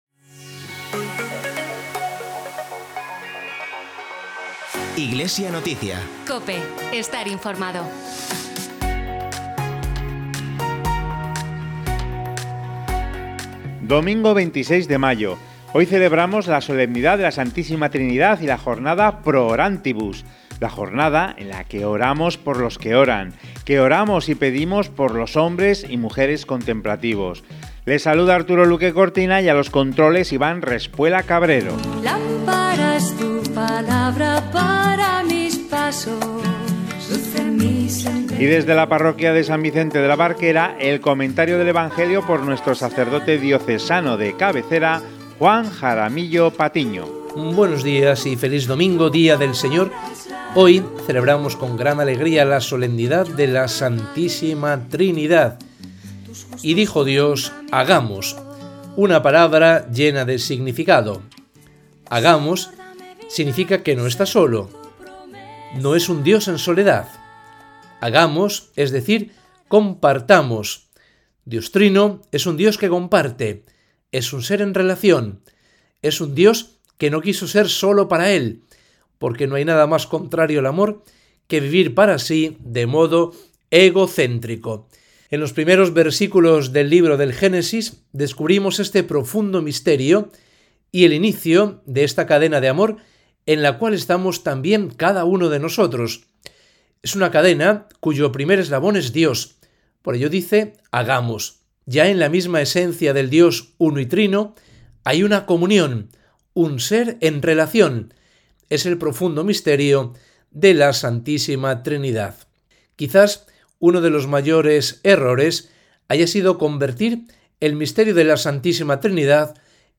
Entrevista a las las Clarisas de Villaverde de Pontones en el monasterio de la Santa Cruz. Profesión de Votos de una joven monja con d. Manuel Sánchez Monge, obispo emérito de Santander.